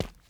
Sounds / sfx / Footsteps / Concrete / Concrete-04.wav